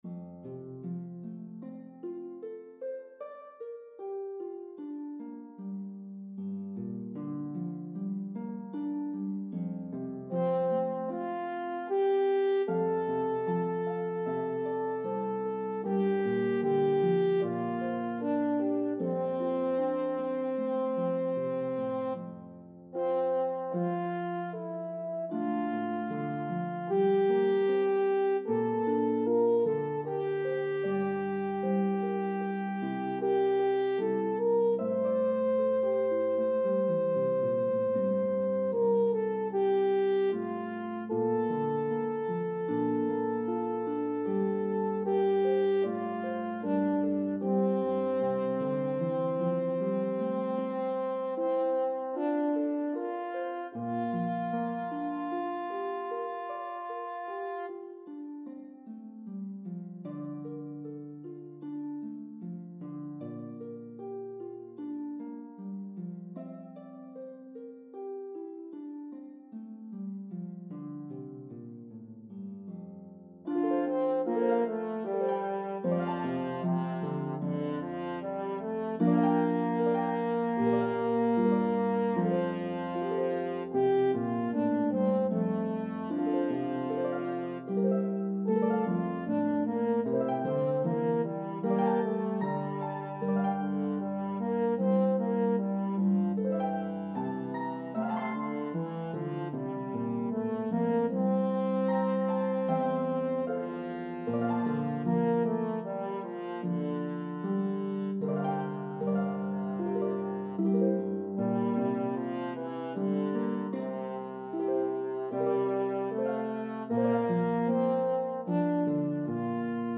Harp and French Horn version